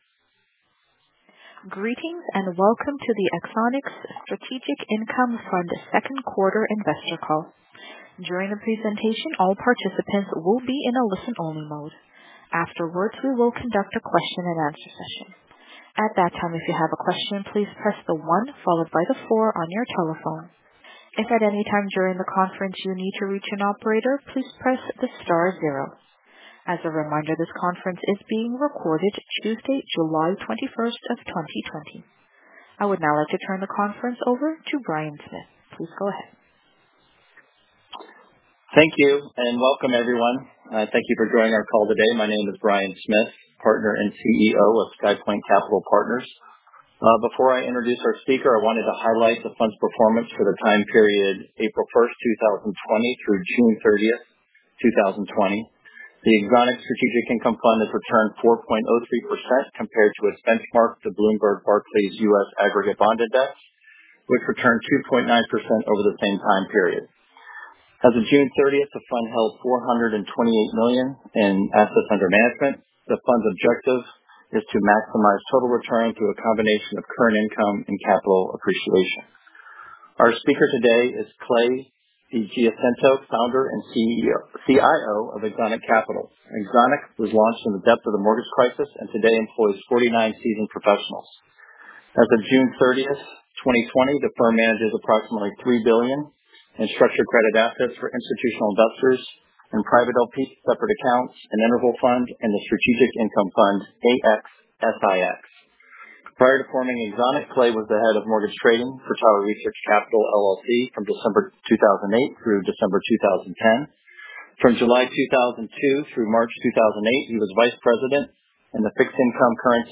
Axonic Strategic Income Fund 2020 Q2 Investor Call Replay | Axonic Funds